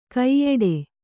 ♪ kaiyeḍe